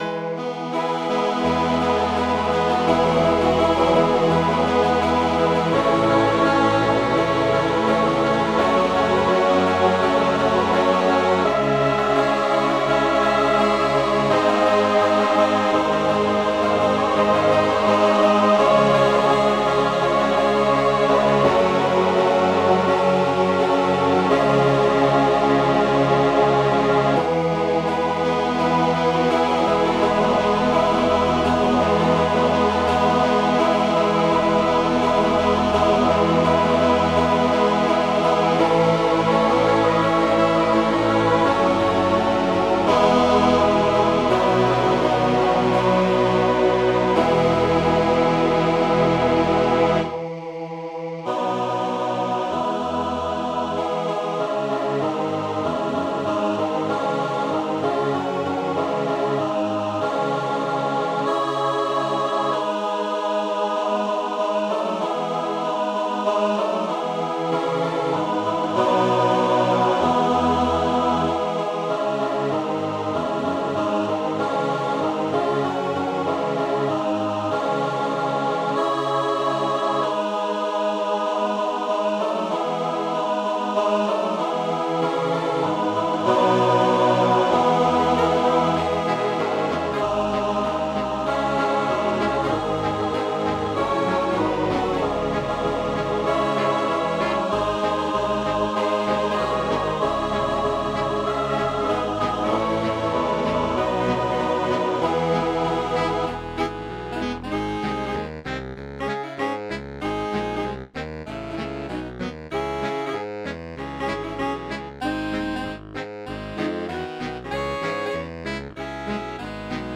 Marienvesper mit Jazz-Resonanzen
Für den ersten Eindruck ambei (Computersounds): Grundarrangement der klassischen Parts =
Besetzung = 8 Saxophone, Chor 1, Chor 2